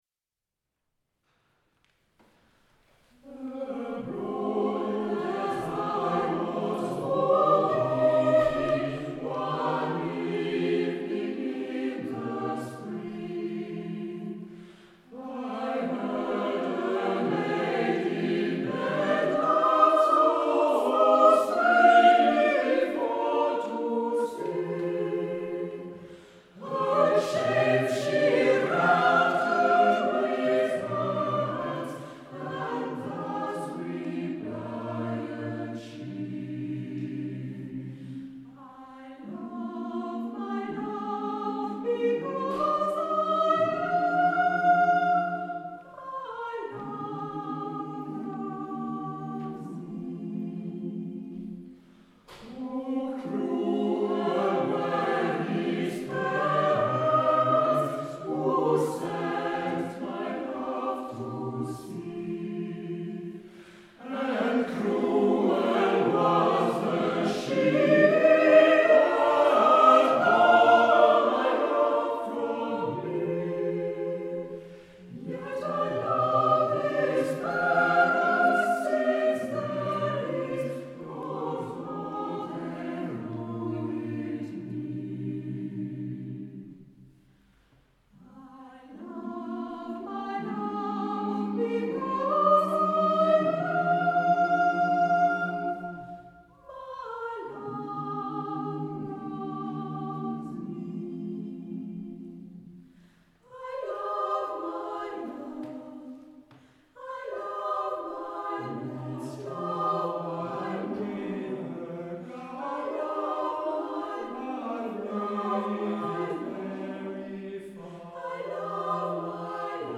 Œuvres a capella des 20e et 21e siècles
Quelques extraits non soumis à des droits d'auteur, avec un bruit de fond impressionnant, désolé ... :
I love my love populaire écossais arrangé par Gustav T. Holst.